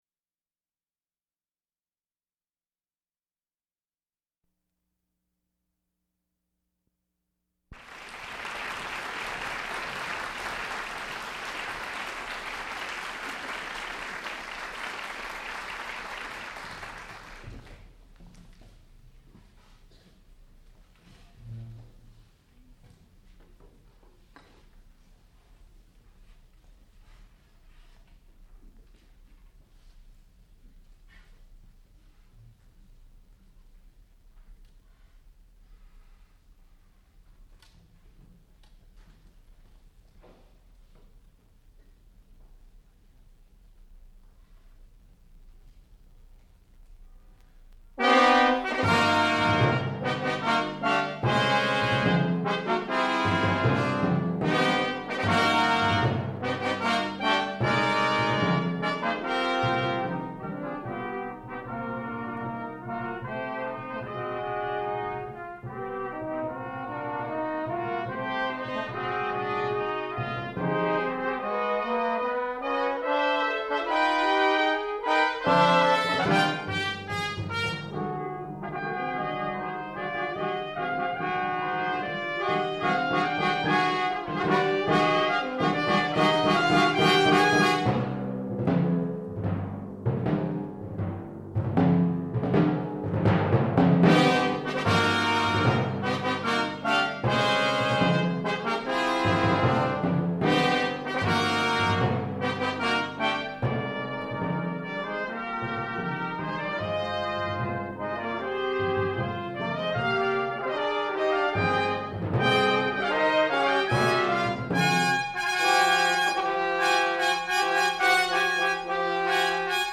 sound recording-musical
classical music
timpani
trombone
trumpet